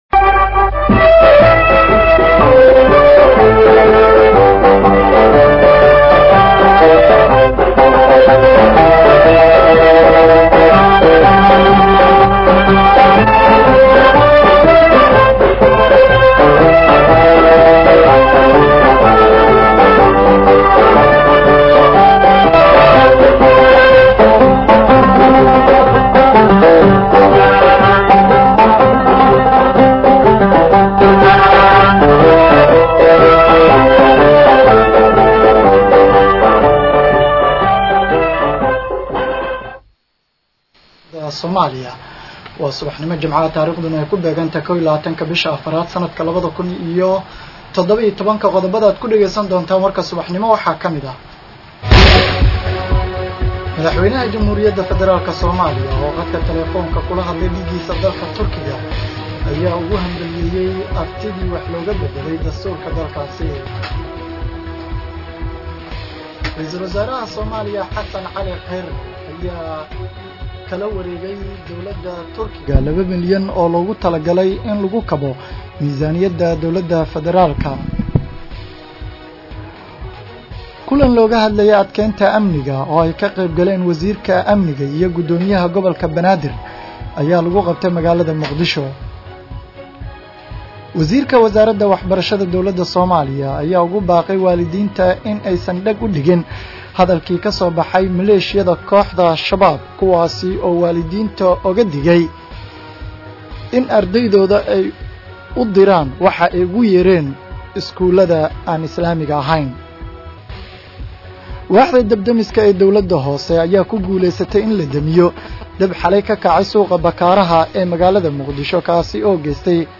Dhageyso warka subax ee Radio Muqdisho